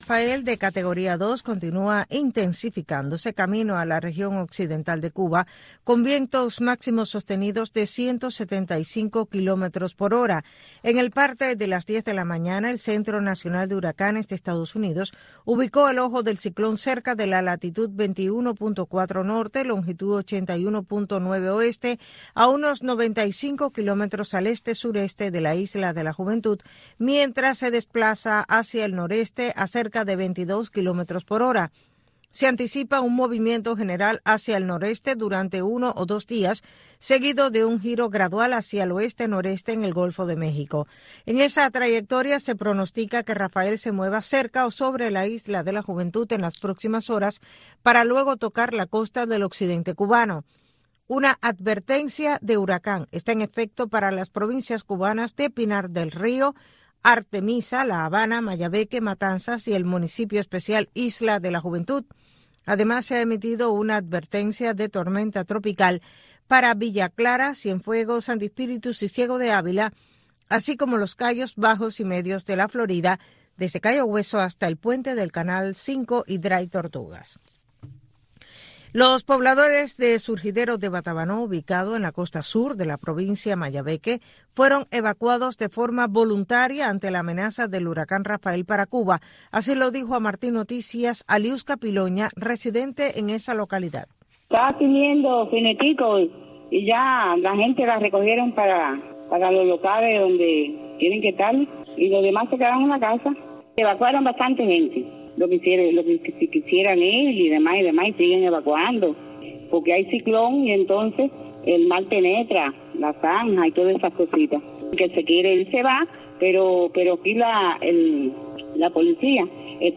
Un espacio radial que va más allá de los nuevos avances de la ciencia y la tecnología, pensado para los jóvenes dentro de la isla que emplean las nuevas tecnologías para dar solución a sus necesidades cotidianas.